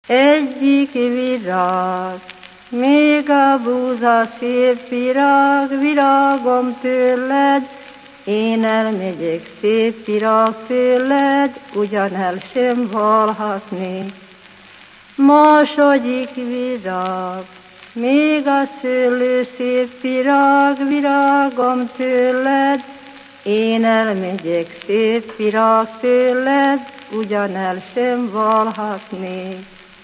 Felföld - Nógrád vm. - Ipolyvarbó
ének
Műfaj: Menyasszonybúcsúztató
Stílus: 5. Rákóczi dallamkör és fríg környezete
Kadencia: 5 (5) 1 1